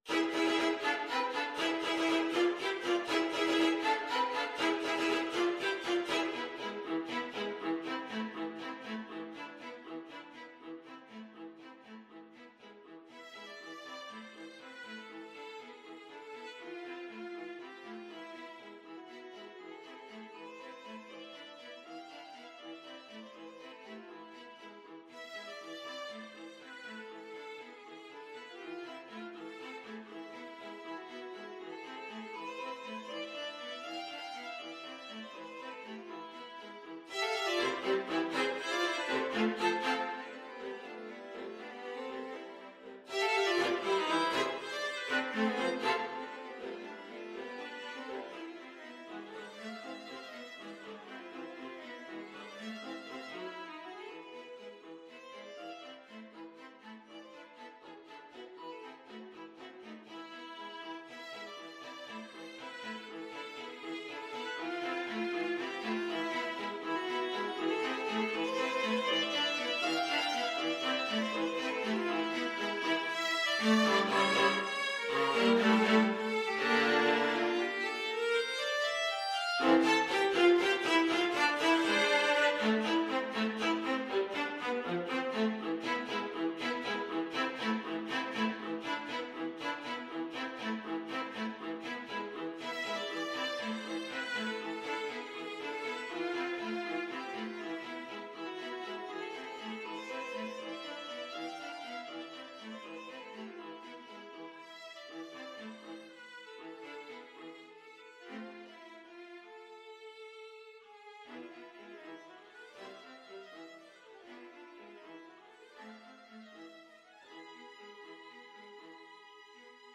3/8 (View more 3/8 Music)
Allegro vivo (.=80) (View more music marked Allegro)
Viola Trio  (View more Advanced Viola Trio Music)
Classical (View more Classical Viola Trio Music)